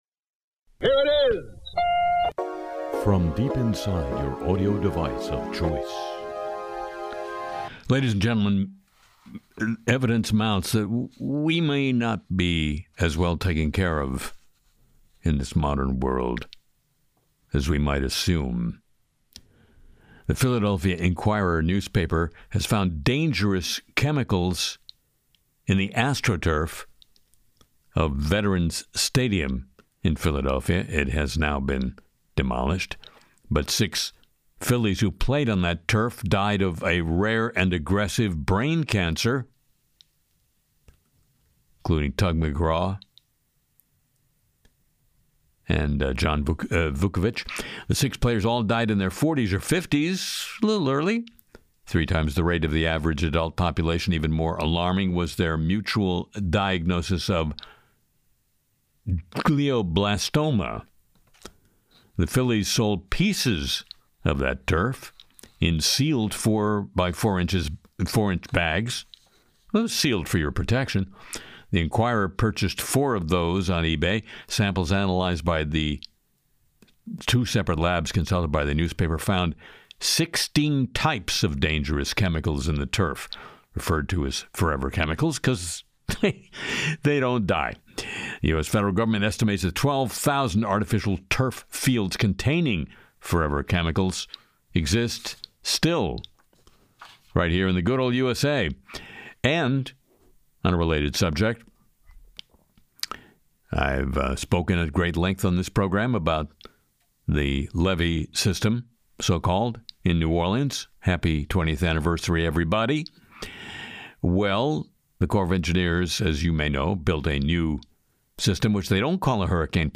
Harry Shearer delivers a week of biting satire: Trump mocked in a hilarious skit, AI Excel Copilot warnings, 9/11 families vs Saudi Arabia, Chatbots vs bad grammar, and Anthropic settles an author suit.